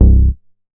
MoogDamm 015.WAV